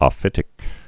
(ŏ-fĭtĭk, ō-fĭt-)